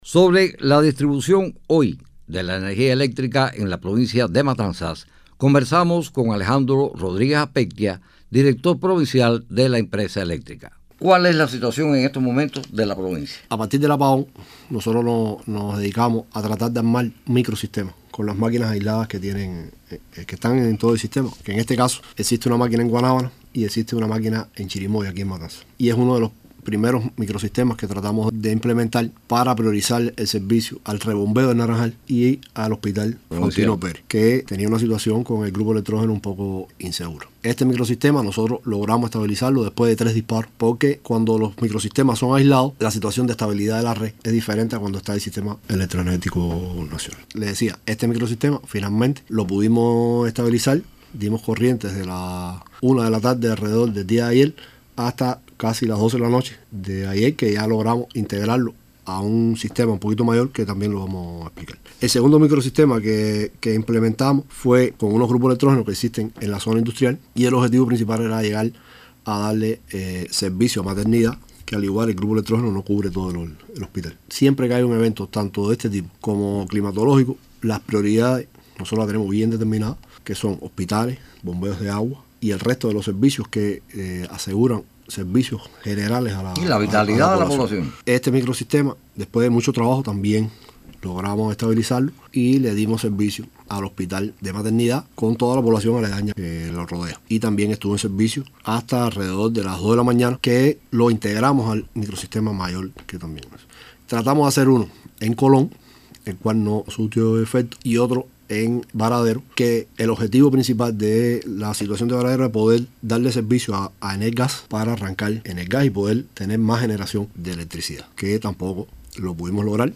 Sobre el tema conversamos